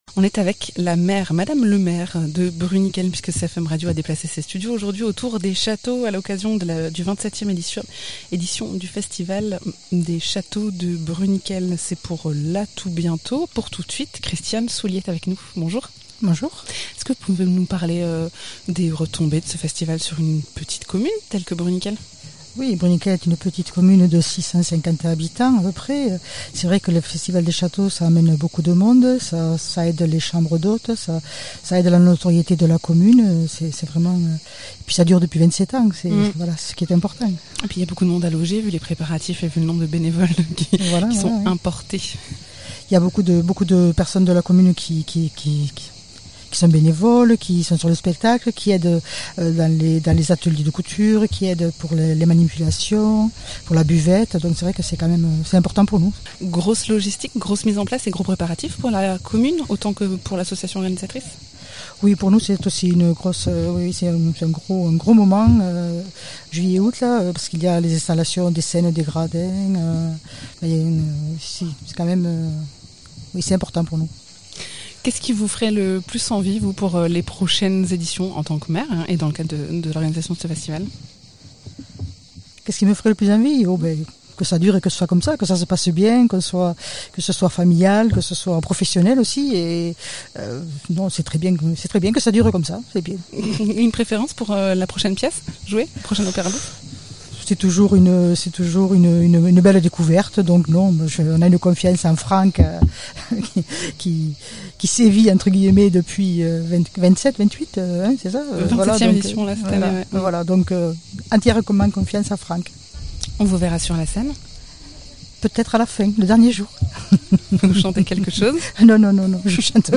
Interviews
Invité(s) : Christiane Soulié, maire de Bruniquel